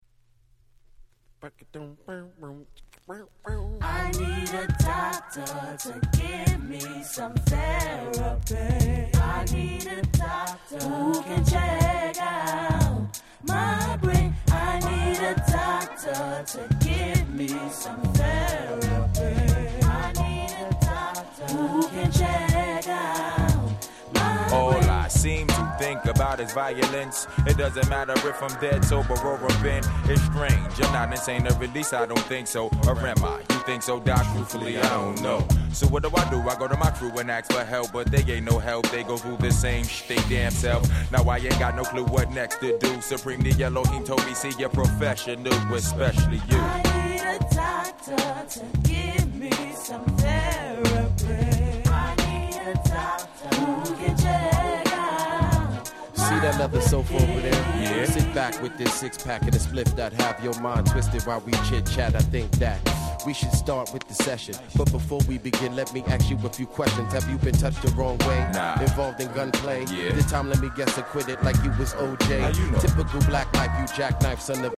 96' Smash Hit Hip Hop !!
Boot Canp物には珍しく、サビに女性ボーカルを迎えたSmoothな1枚。